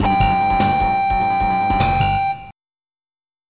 < prev next > MIDI Music File